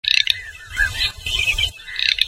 Le Canard Chipeau